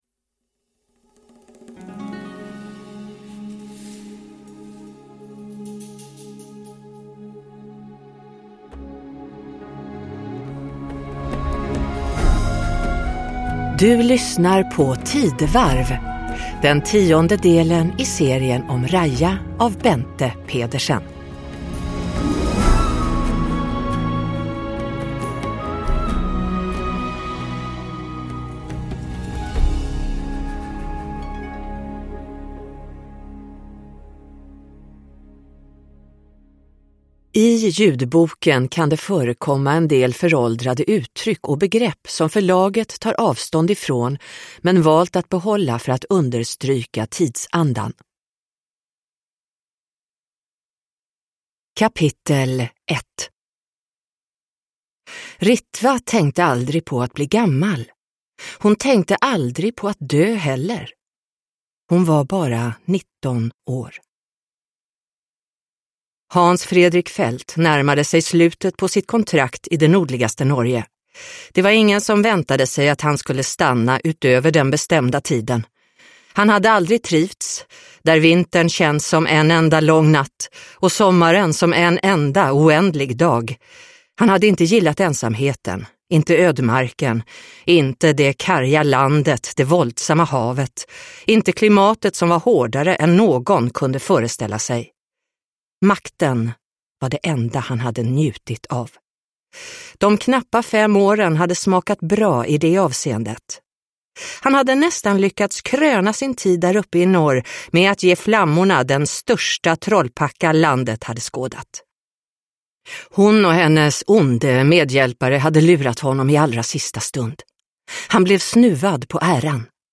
Tidevarv – Ljudbok